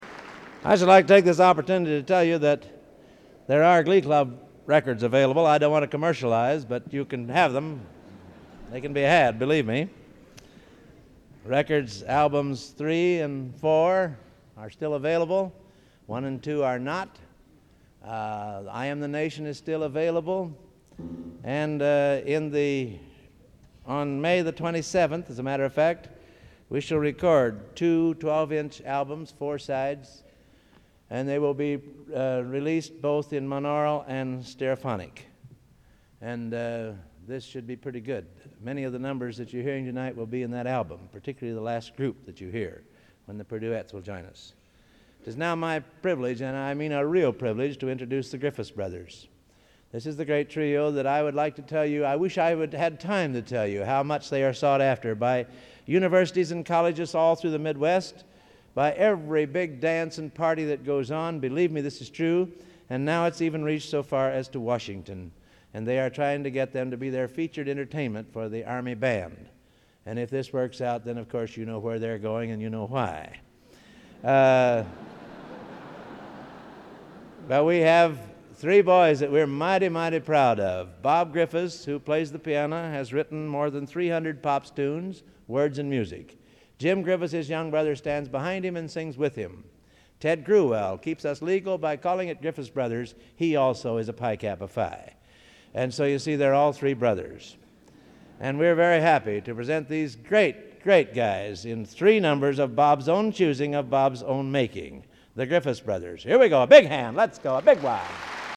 Collection: Gala Concert, 1961
Genre: | Type: Director intros, emceeing